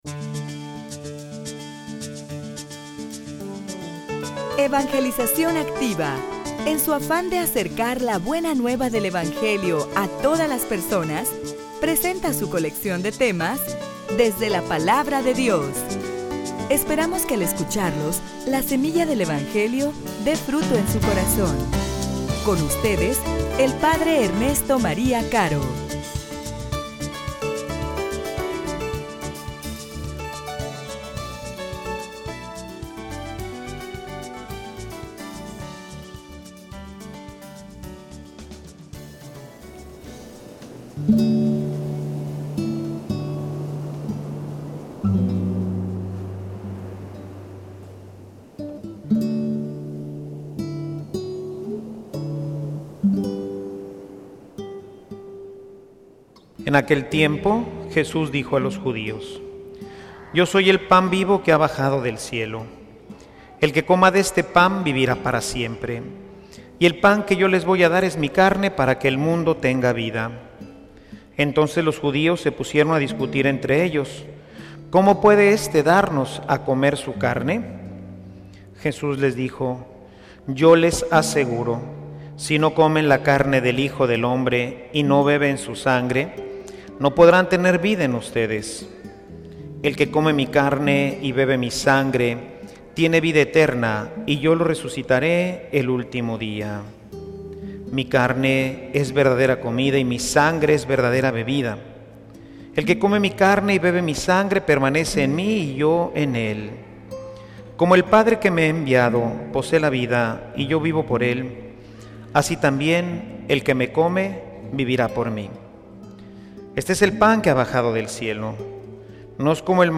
homilia_El_Pan_para_la_vida_eterna.mp3